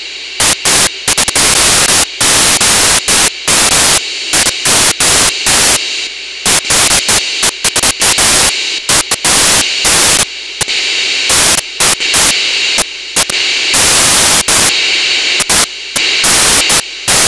Increasing the volume control slightly and the unit starts to chuff , adjusting the other control increases the speed of the chuff thus by a combination of both you can get from just a hiss to full speed .